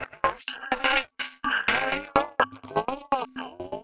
Index of /90_sSampleCDs/Sample Magic - Transmission-X/Transmission-X/transx loops - 125bpm